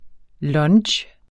Udtale [ ˈlʌndɕ ]